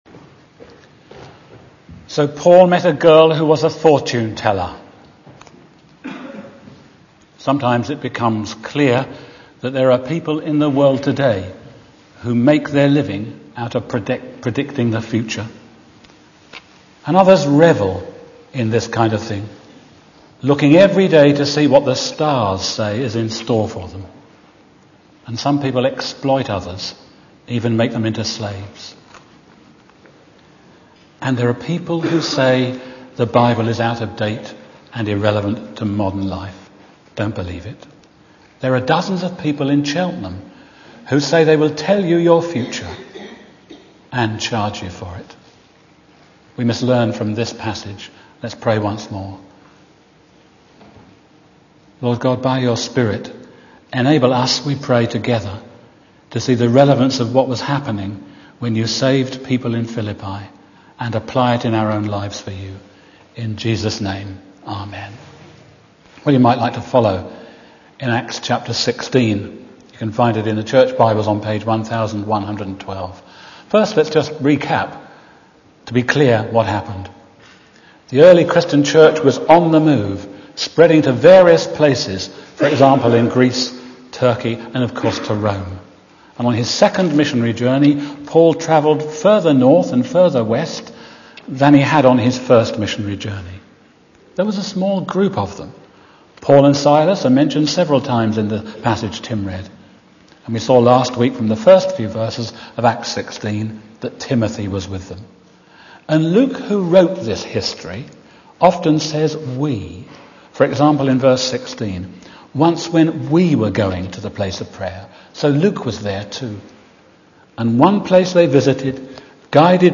2011 Service type: Sunday PM Bible Text: Acts 16:16-40